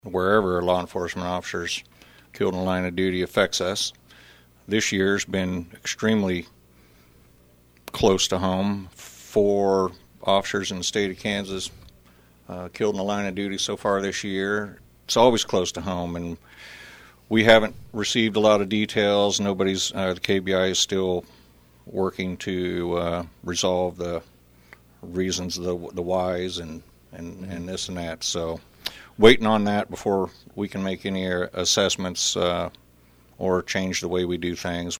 Emporia Police Chief Ed Owens, on the most recent installment of KVOE’s Monthly Q&A segment, says anytime an officer is injured or killed in the line of duty, it impacts all law enforcement agencies, including Emporia.